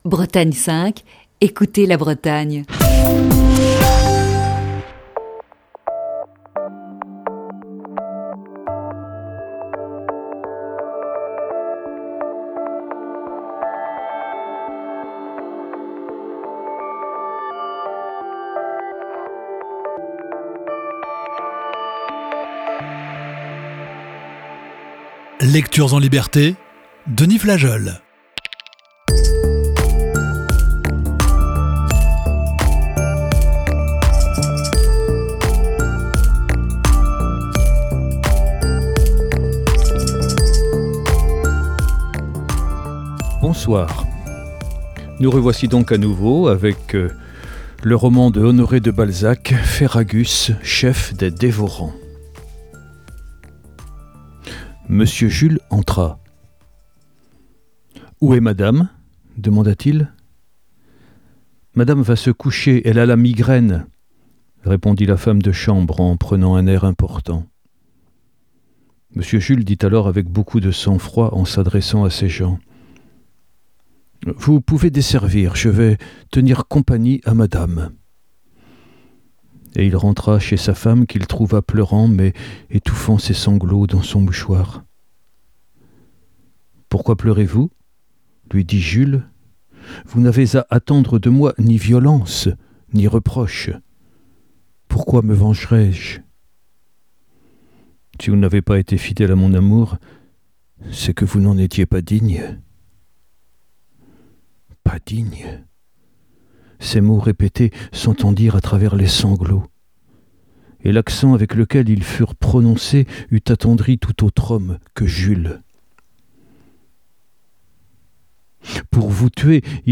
Voici ce soir la huitième partie de ce récit.